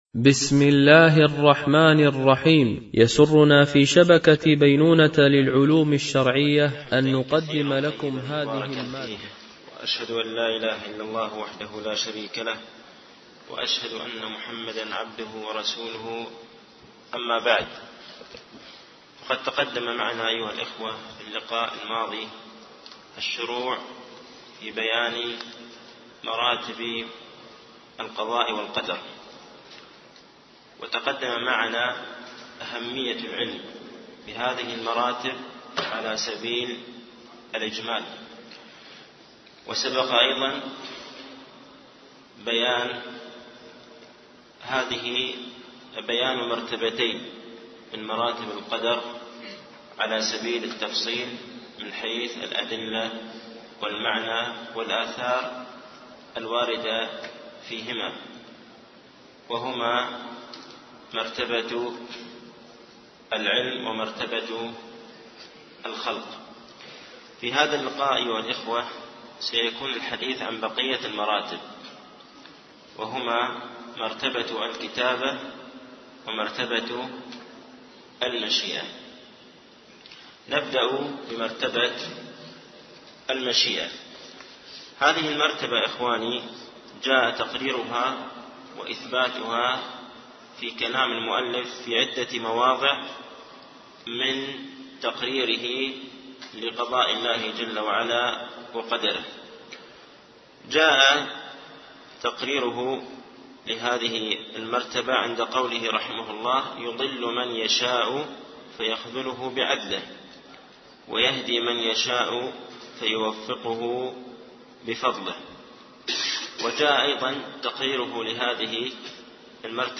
شرح مقدمة ابن أبي زيد القيرواني ـ الدرس الثاني و العشرون